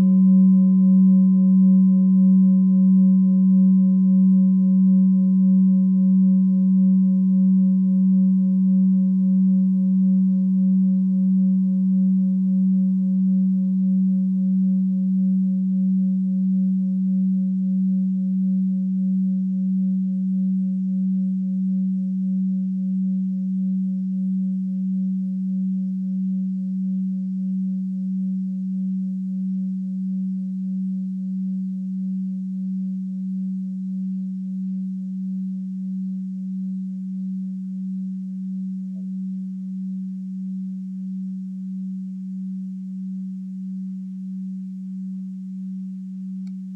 Bengalen Klangschale Nr.6 Planetentonschale: Eros-Ton
Klangschale Bengalen Nr.6
Sie ist neu und wurde gezielt nach altem 7-Metalle-Rezept in Handarbeit gezogen und gehämmert.
(Ermittelt mit dem Filzklöppel oder Gummikernschlegel)
Hören kann man diese Frequenz, indem man sie 32mal oktaviert, nämlich bei 154,66 Hz. In unserer Tonleiter befindet sich diese Frequenz nahe beim "D".
klangschale-ladakh-6.wav